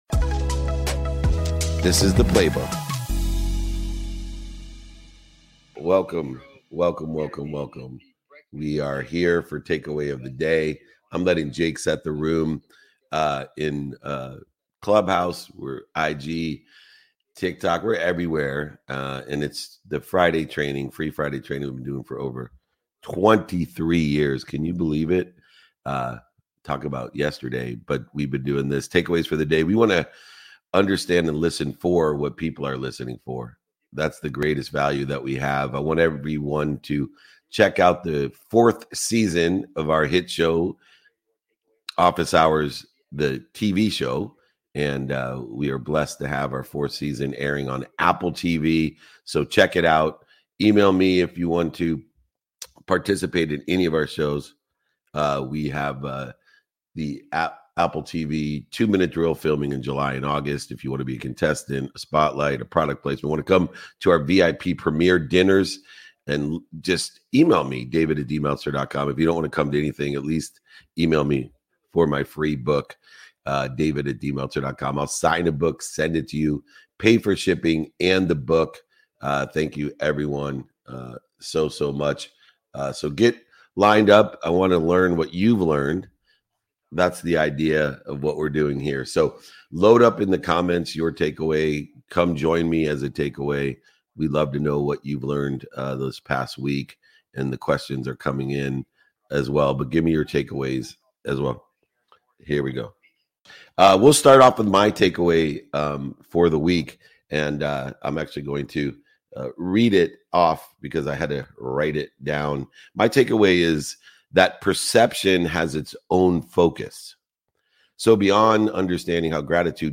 dynamic conversation